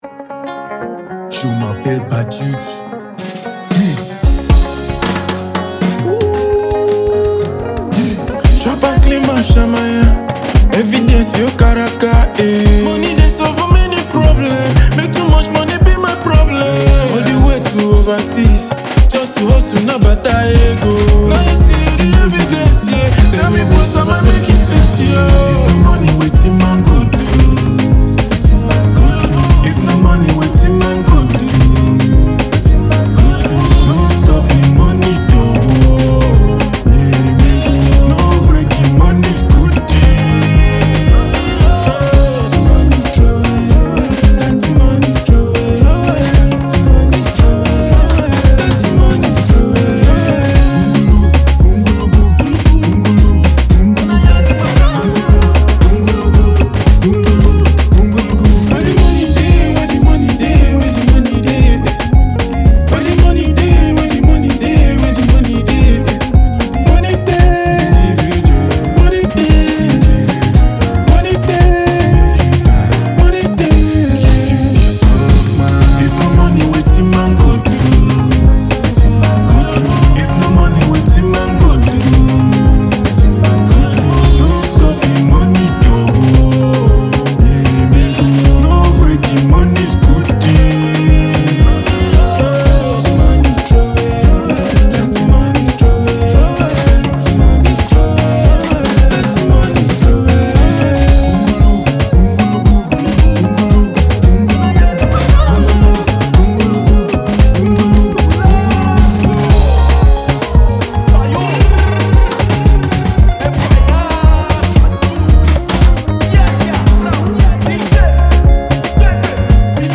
Afro-inspired songs